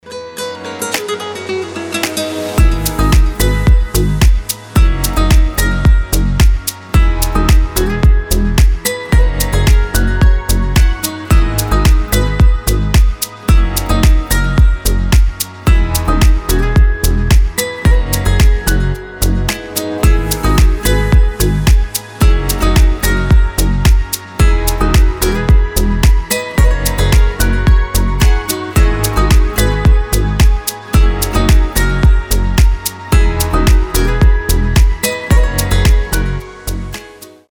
• Качество: 320, Stereo
гитара
deep house
без слов
красивая мелодия
Chill House
Стиль: chill, deep house